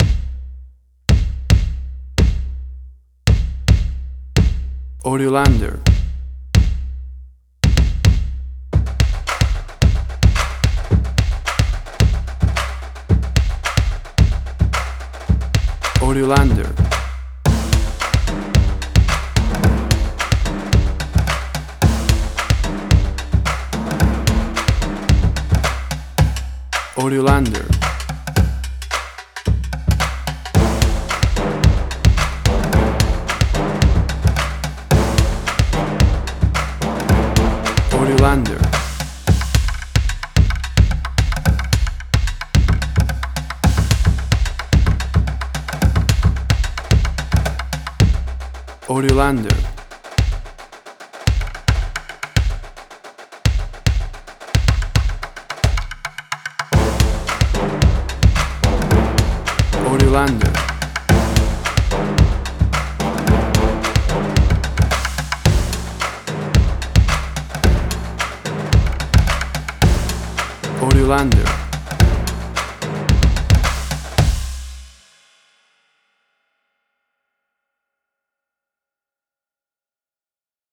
WAV Sample Rate: 16-Bit stereo, 44.1 kHz
Tempo (BPM): 110